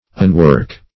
Search Result for " unwork" : The Collaborative International Dictionary of English v.0.48: Unwork \Un*work"\ ([u^]n*w[^u]k"), v. t. [1st pref. un- + work.]